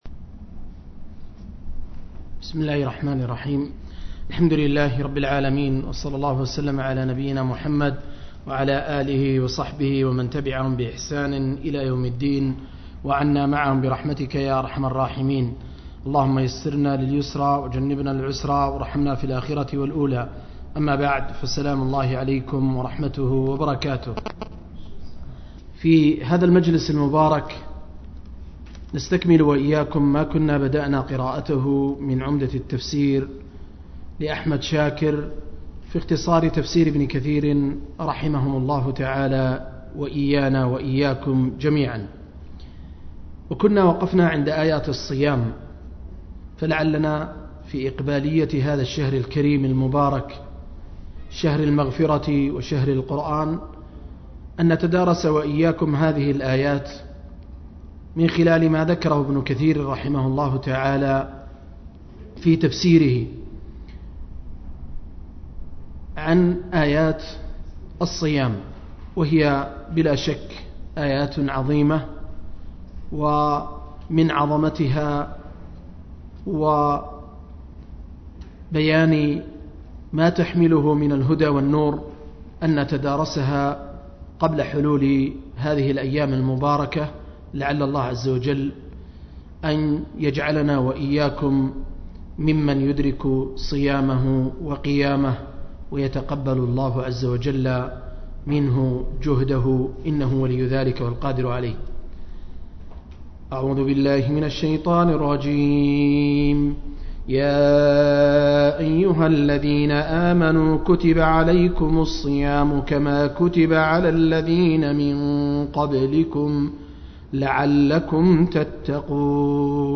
036- عمدة التفسير عن الحافظ ابن كثير – قراءة وتعليق – تفسير سورة البقرة (الآيات 185-183)